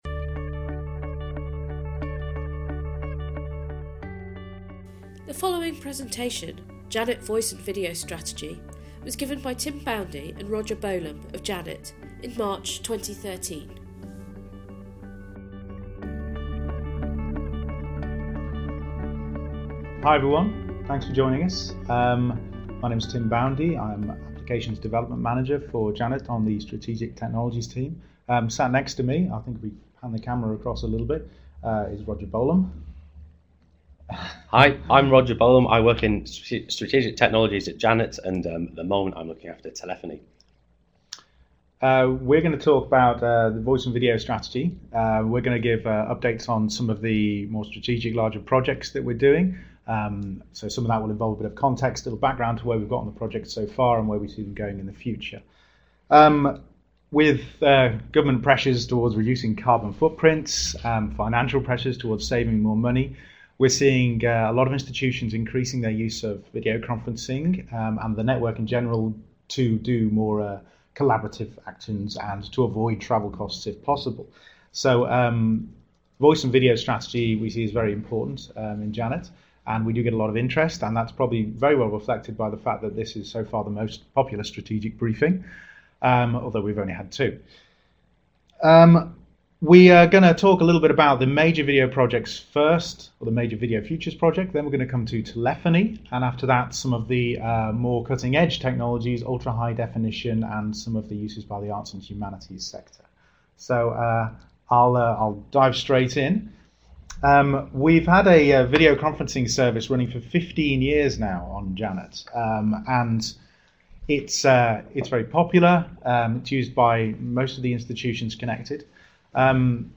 During this briefing we will be discussing Janet's strategic approach towards developing the next generation of video collaboration services and supporting IP telephony across Janet.